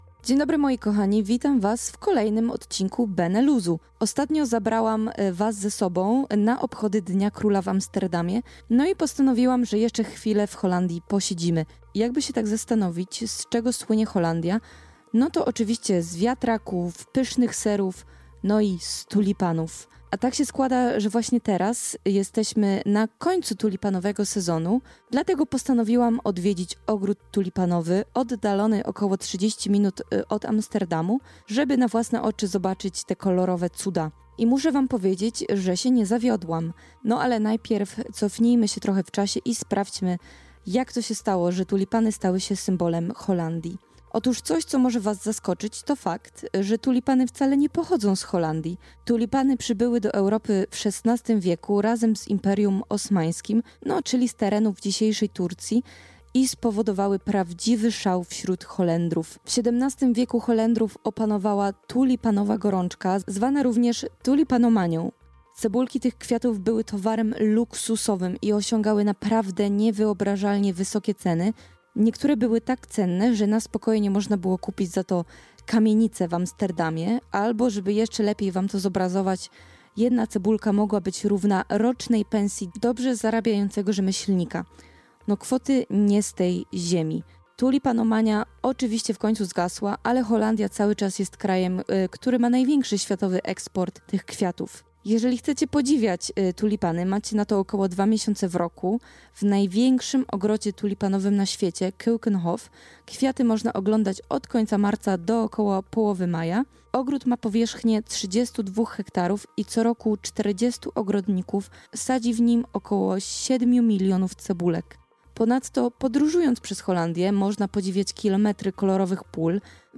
Byliśmy w ogrodzie Keukenhof - relacja Radio LUZ